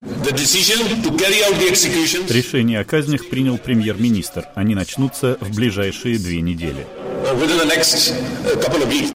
Говорит министр внутренних дел Пакистана Чоудри Нисар Али Хан